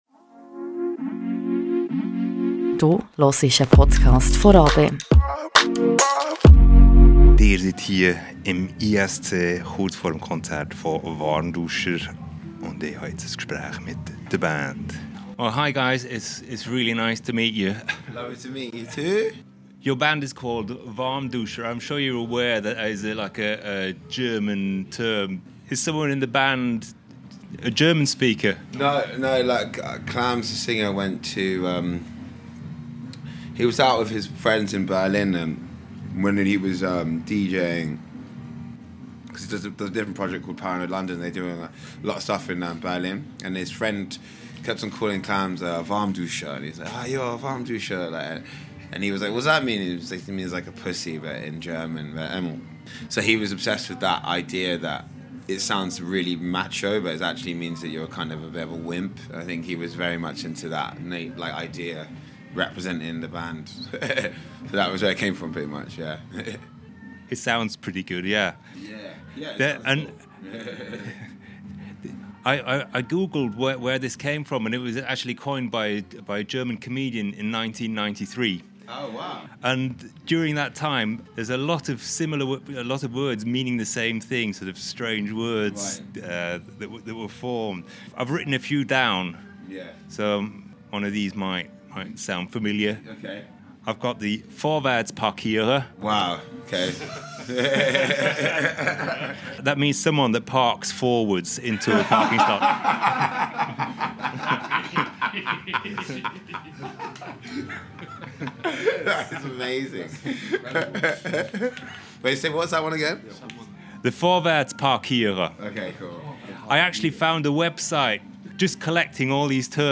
Amplifier-Interview with Warmduscher ~ Radio RaBe Podcast
Before they played at the ISC-Club in Bern Warmduscher sat down with me for a chat.